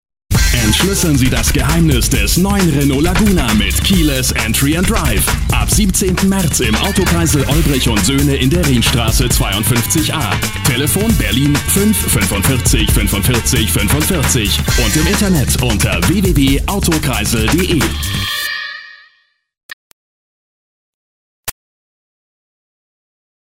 deutscher Sprecher
Kein Dialekt
Sprechprobe: eLearning (Muttersprache):
german voice over artist